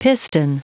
Prononciation du mot piston en anglais (fichier audio)
Prononciation du mot : piston